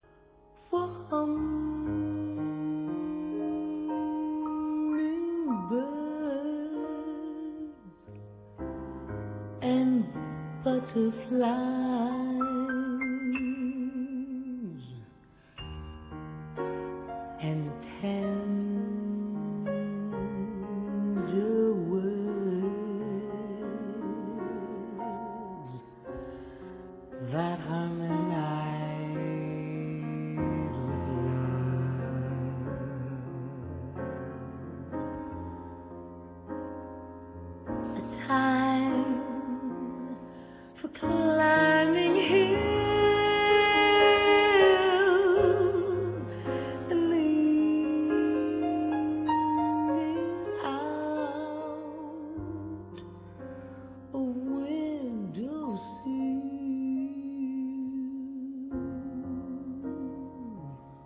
最让人心醉的是第一段女声录音。